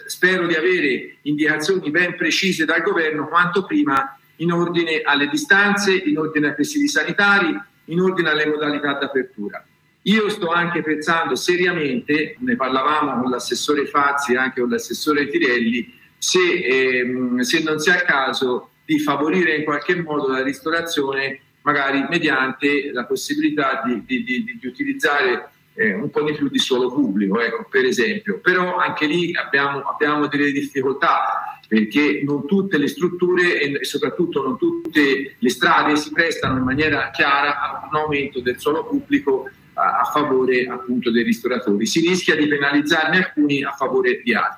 Nel consueto appuntamento con il sindaco Luigi De Mossi, in videoconferenza con i media locali, si è parlato oggi di attività commerciali e culturali.
DE-MOSSI-RISTORANTI.mp3